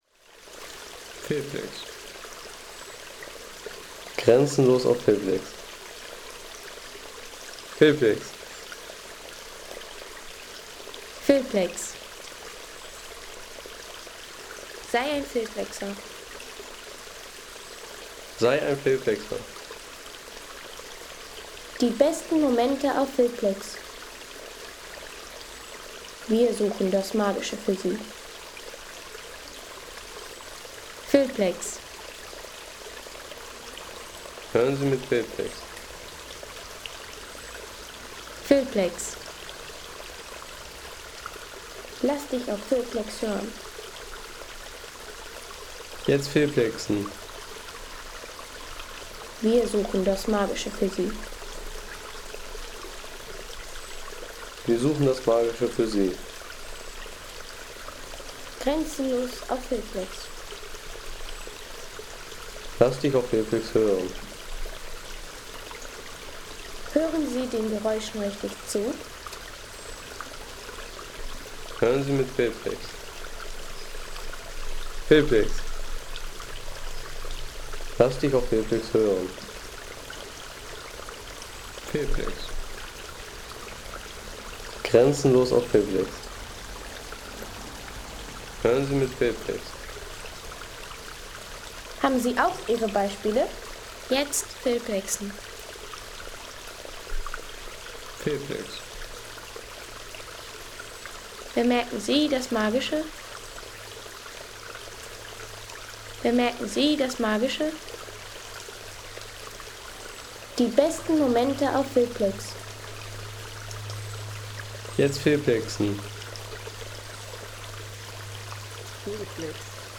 Krailsbach Soundeffekt für Film und ruhige Naturszenen
Krailsbach | Sanfte Bachatmosphäre für Film und Sounddesign
Sanfte Bachatmosphäre vom Krailsbach in Müden.
Ruhige Bachkulisse mit sanft fließendem Wasser und natürlichem Charakter für Filme, Postcards, Reiseclips und stimmungsvolle Szenen.